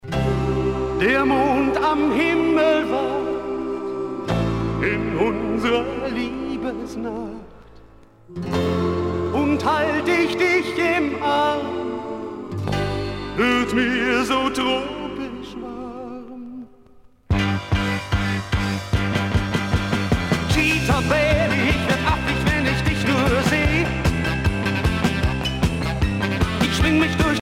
danse : rock